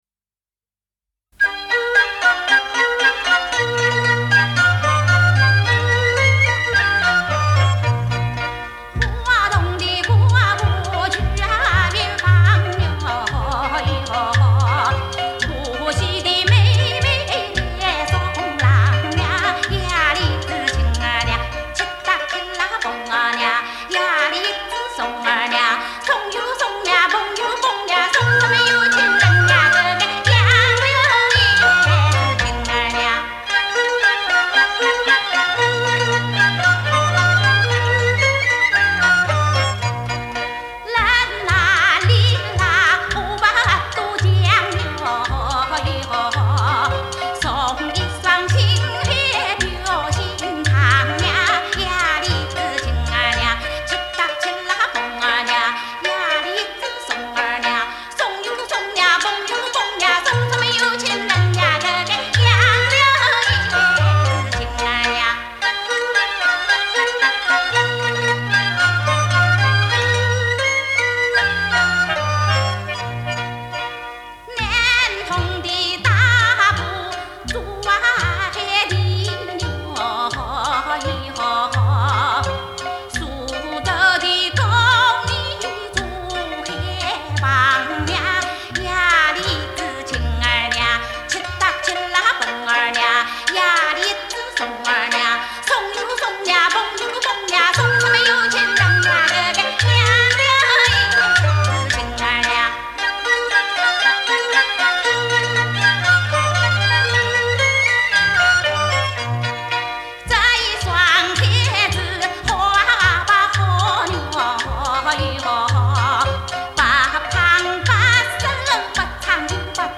杨州民歌